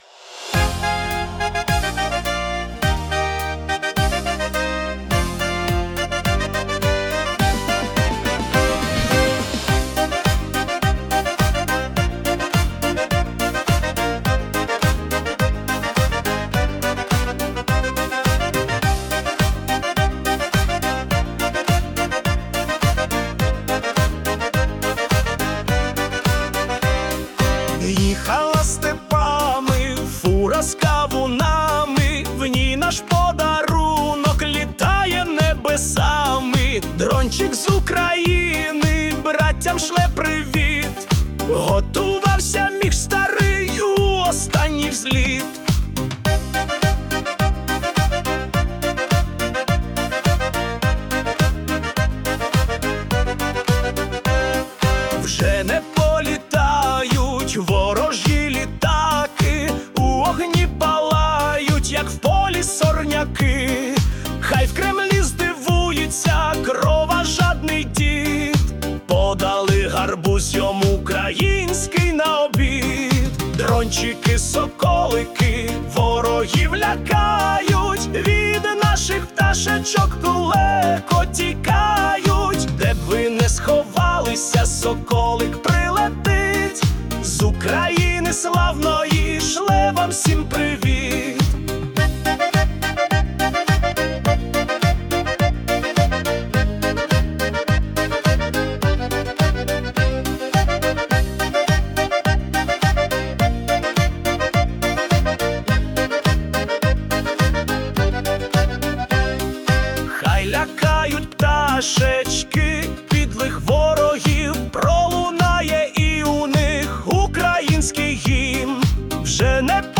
P.S.: пісня створена з використанням платної версії АІ Suno
ТИП: Пісня
СТИЛЬОВІ ЖАНРИ: Мілітариський